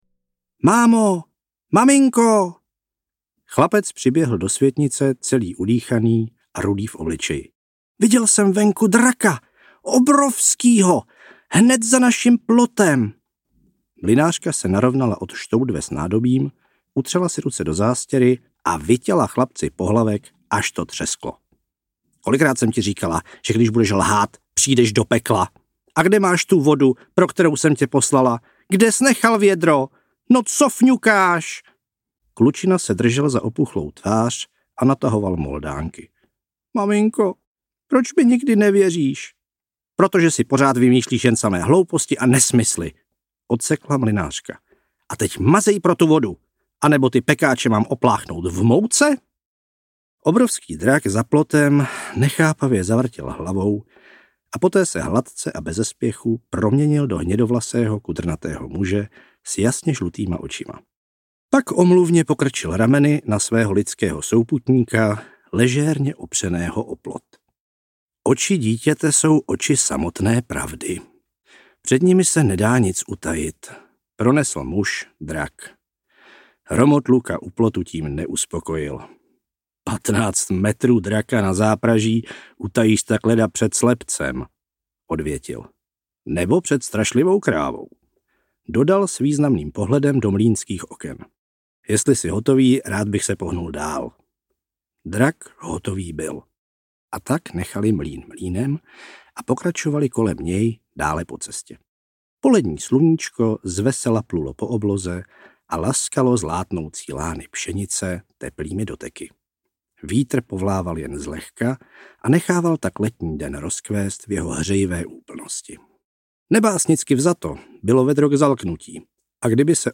Audiokniha
A navíc si to tentokrát načetl sám!